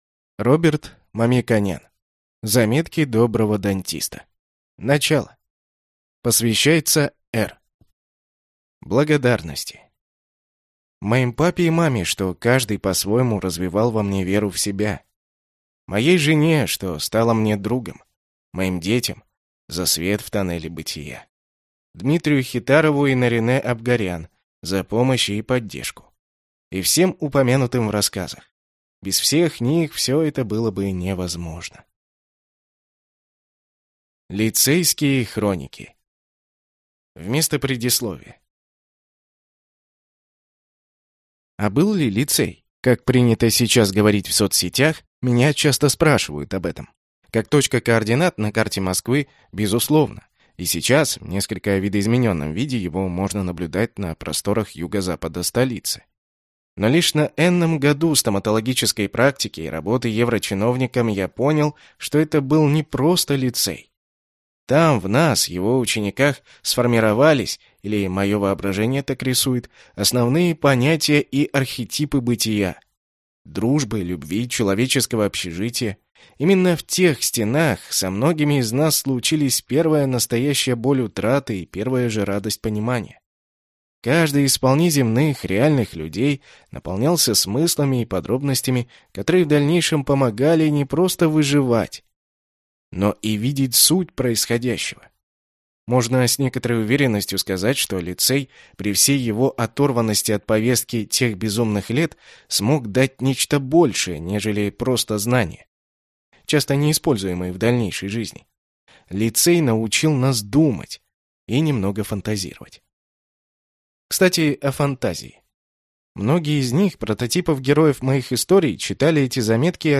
Аудиокнига Заметки доброго дантиста. Начало | Библиотека аудиокниг